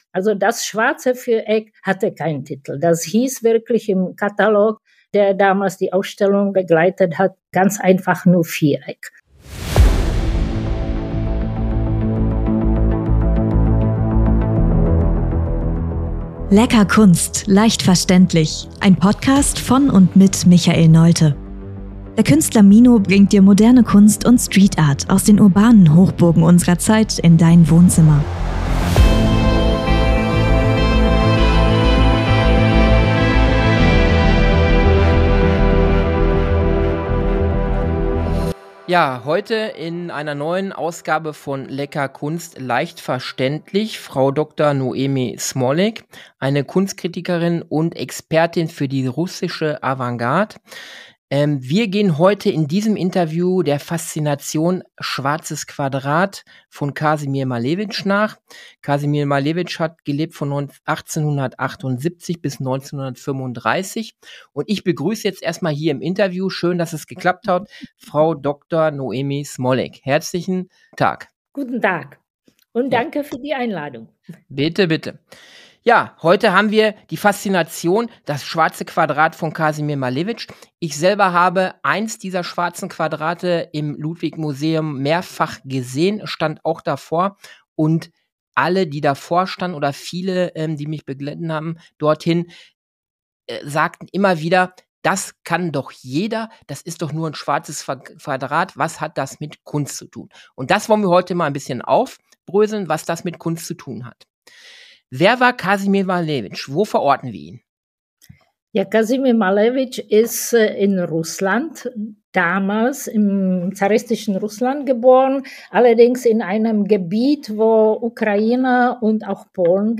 in einem exklusiven Gespräch